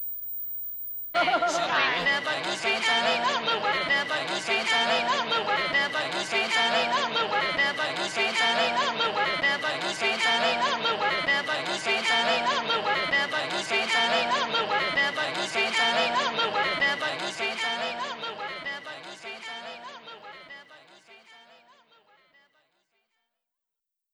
3rd Grade Choir Presents Love & Life with the Beatles | Notes from a Composer
18:40 “A Day in the Life” – with “Within You Without You” the other song where I took the greater musical chances.  For 3rd grade sensibilities the verse about the fatal car crash was taken out, and instead of having a smoke they sung about having a coke, but otherwise the performance is true to all the elements of the classic music history changing recording, including a vocal version of the famous orchestral rising cacophony, the humming of the extended final chord, and, for those who remember old school vinyl records, that final “never could be any other way” that would be repeated over and over again until someone lifts the needle off the “Sergeant Pepper’s Lonely Hearts Club Band” record: